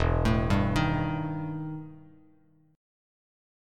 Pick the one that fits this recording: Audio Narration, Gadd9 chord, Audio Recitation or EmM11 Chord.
EmM11 Chord